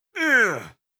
RiftMayhem / Assets / 1-Packs / Audio / NPC or Player / Damage Sounds / 12.
12. Damage Grunt (Male).wav